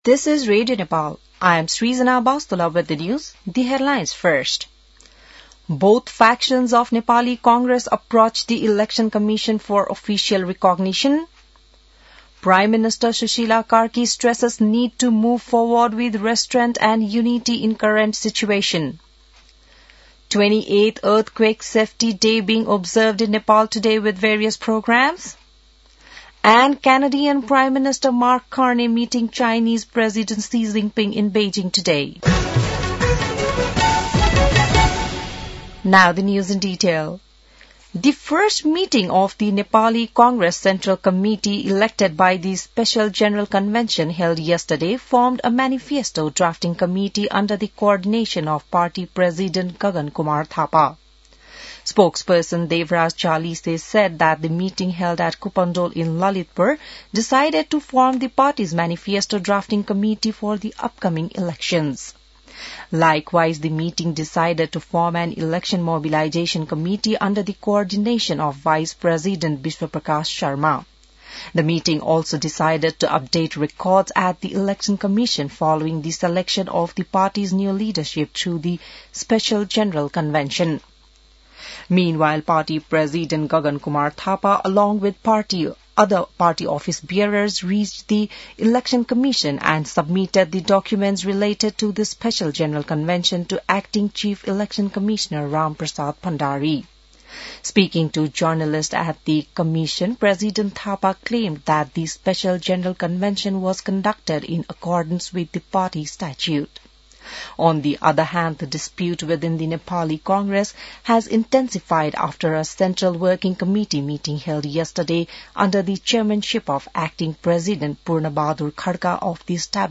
बिहान ८ बजेको अङ्ग्रेजी समाचार : २ माघ , २०८२